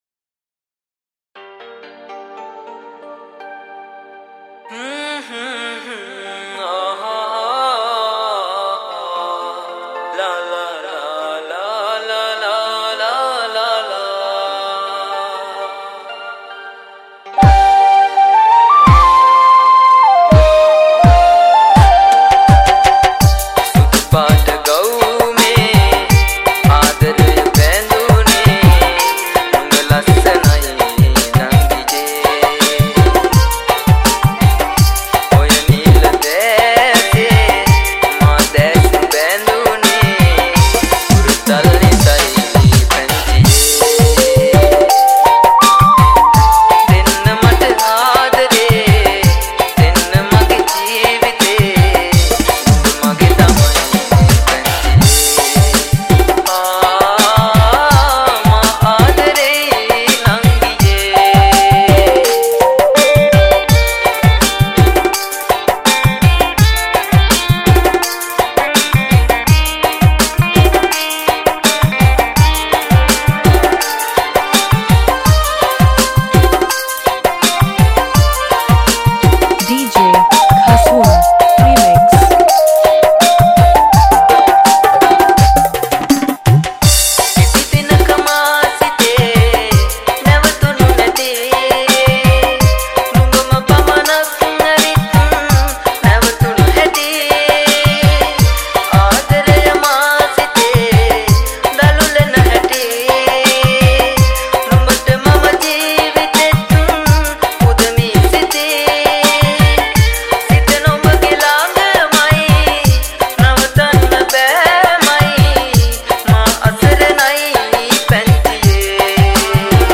High quality Sri Lankan remix MP3 (6.7).